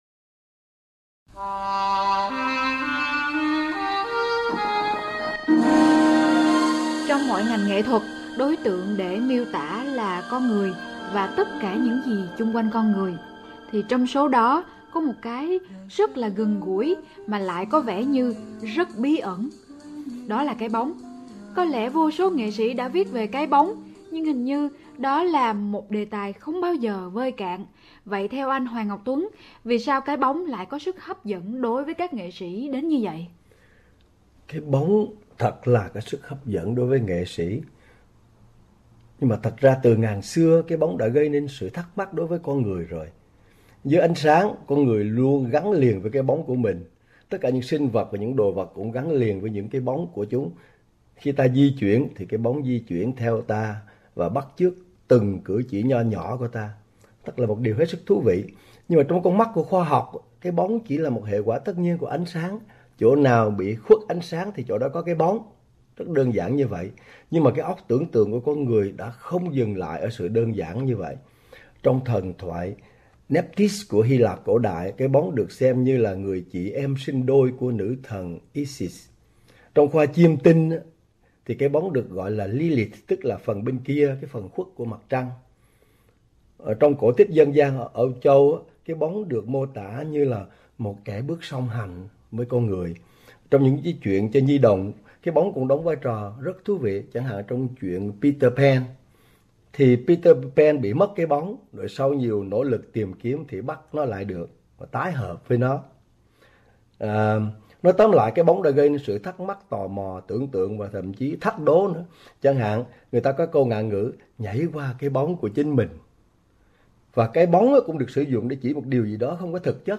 đã thực hiện những cuộc nói chuyện truyền thanh dài khoảng 15 phút vào mỗi đêm Chủ Nhật dưới hình thức phỏng vấn với nhà văn